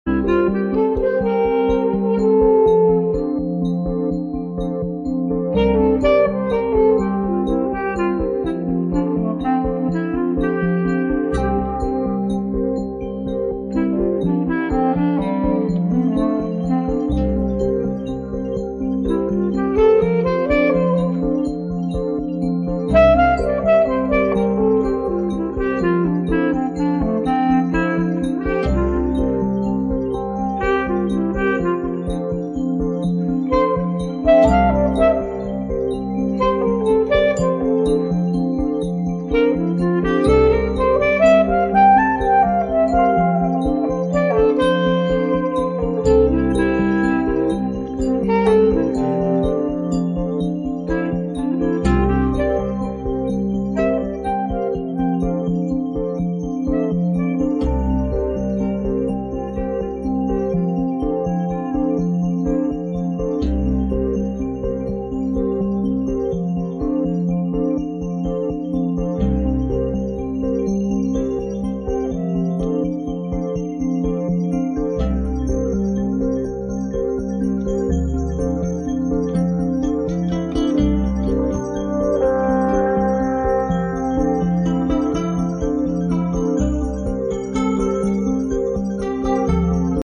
NYC post punk / new wave
4 tracks of pure Downtown punk-dub-fun